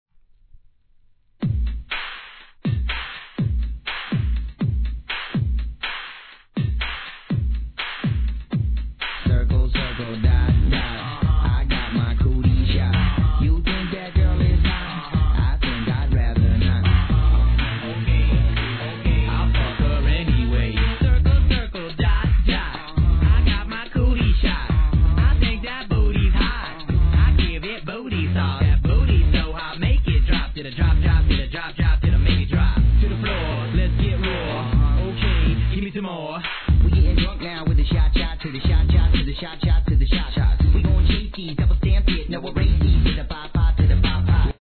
HIP HOP/R&B
エレクトリックなOld Schoolライクなトラックのアッパーパーティチューン!!